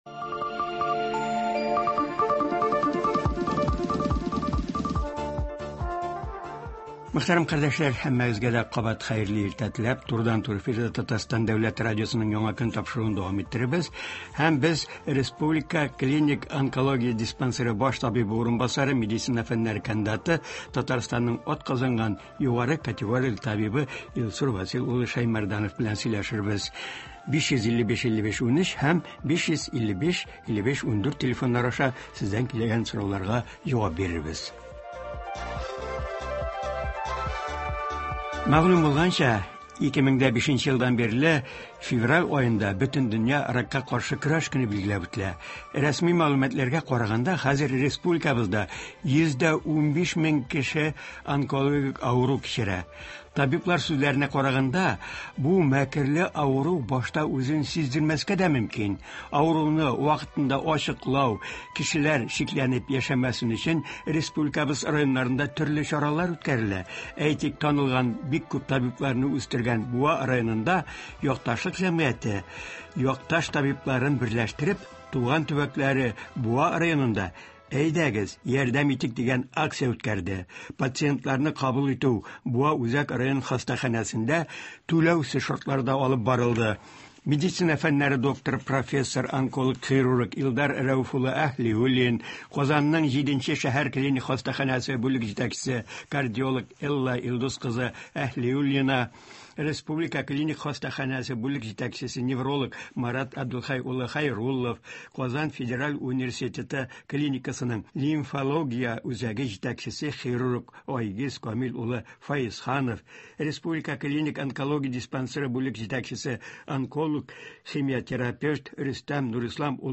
Туры эфир(13.02.23)